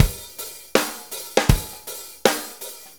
Shuffle Loop 23-05.wav